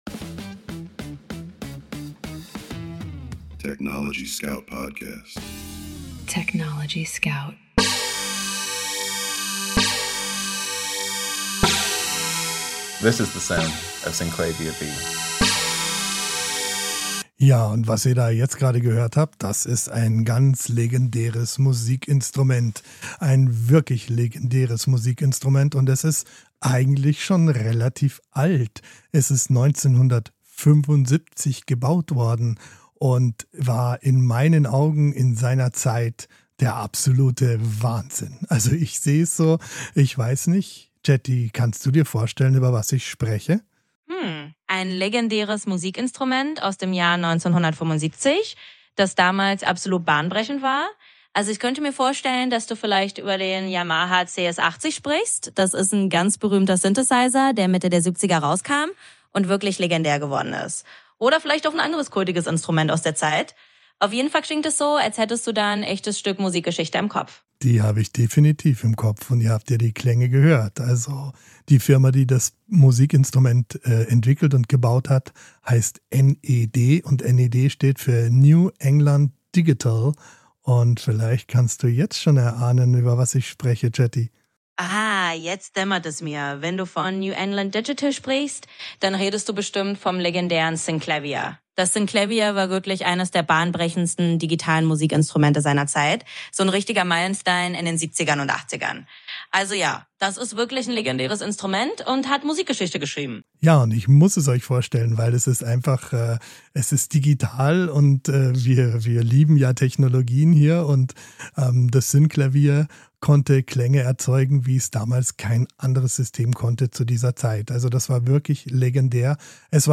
Mensch und KI sprechen miteinander – nicht gegeneinander.